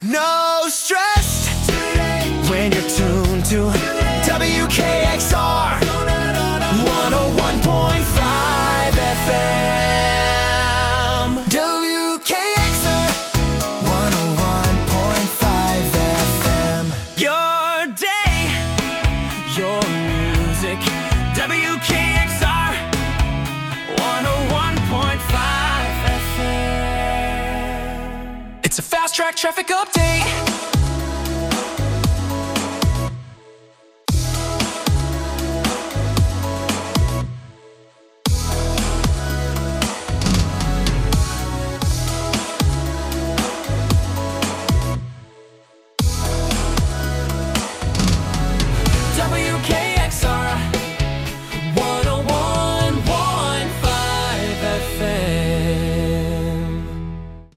Adult Hits
Adult Contemporary Format
Radio Jingles